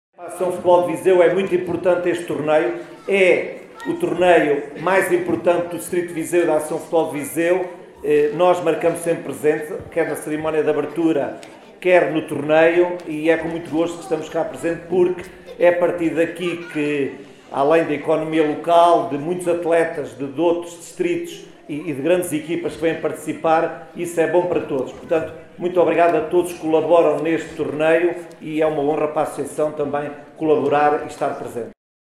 Na tarde deste sábado, 12 de abril, decorreu no Auditório Municipal de Vila Nova de Paiva, a apresentação oficial do 10º Torneio de Futebol Infantil – Paiva Cup 2025, que se vai realizar na próxima sexta-feira, 18 de abril, nos escalões de sub 9 e sub 10 e sábado, dia 19, nos escalões de sub 12 e sub 13.